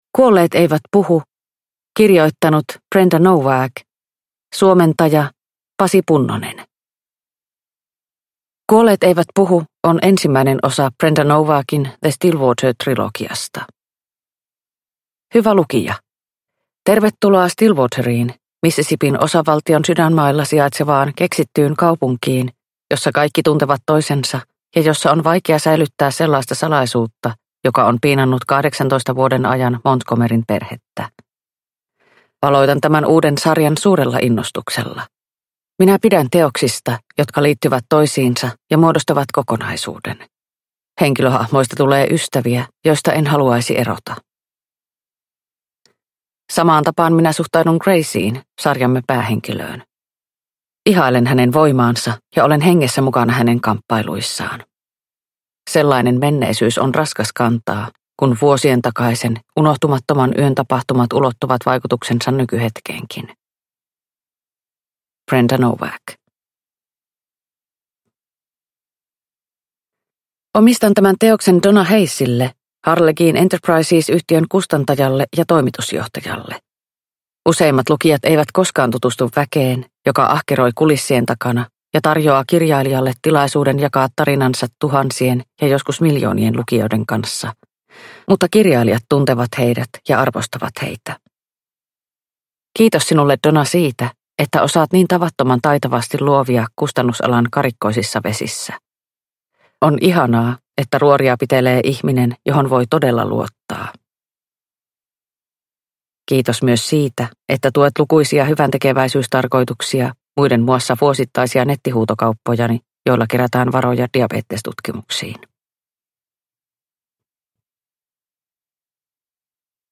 Kuolleet eivät puhu – Ljudbok – Laddas ner